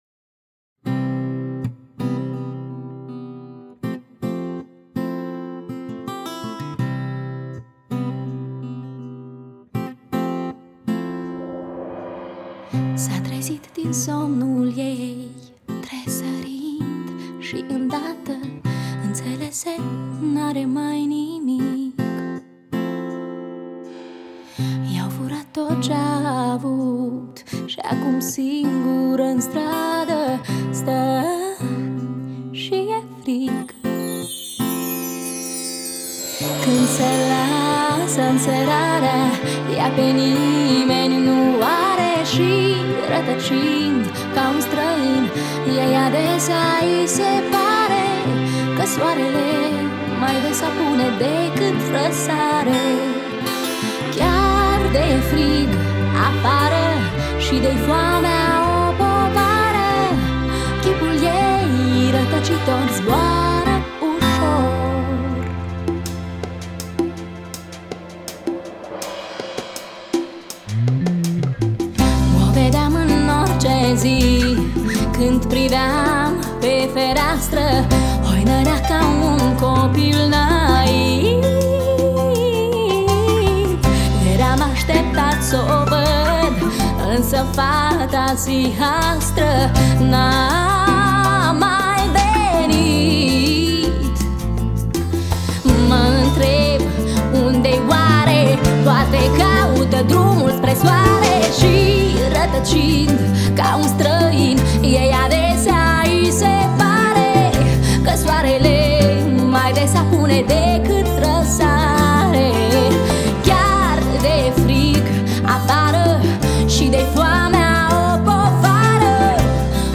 chitară, vioară, voce
saxofon, clarinet, fluier, caval
baterie